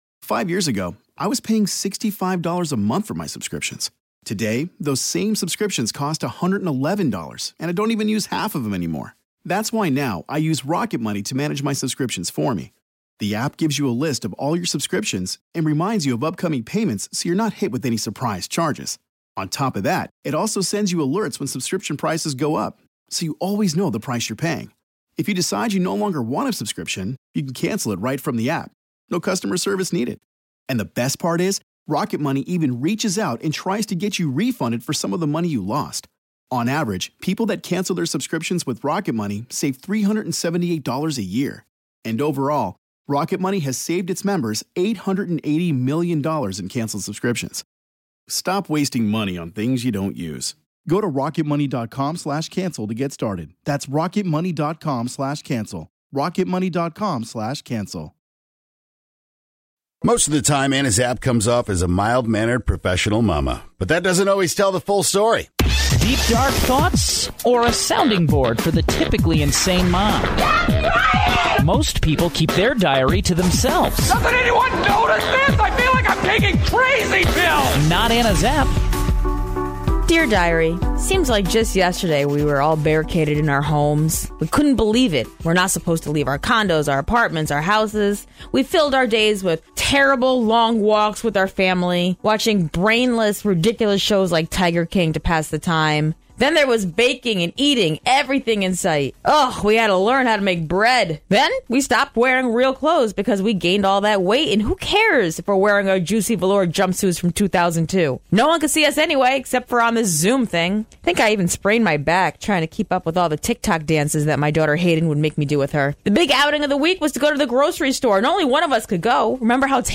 A Reading